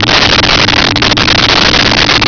Sfx Holosteady Loop2
sfx_holosteady_loop2.wav